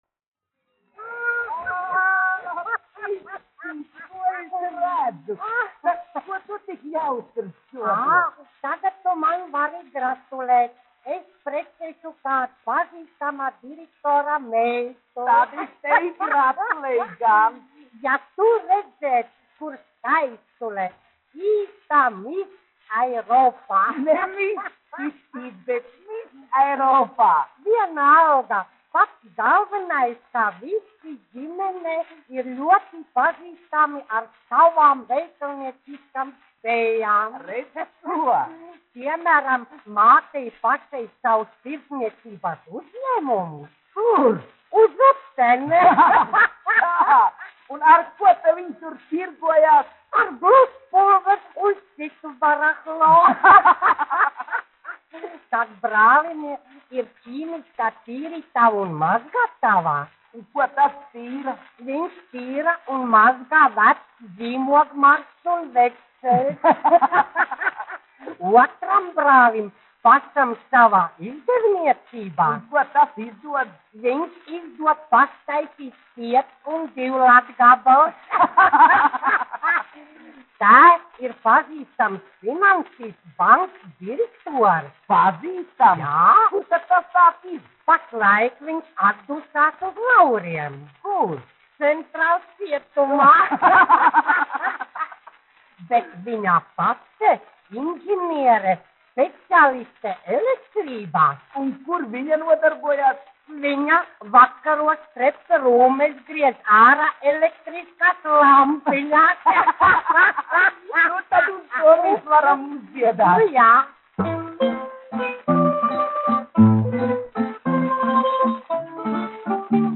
1 skpl. : analogs, 78 apgr/min, mono ; 25 cm
Humoristiskās dziesmas
Latvijas vēsturiskie šellaka skaņuplašu ieraksti (Kolekcija)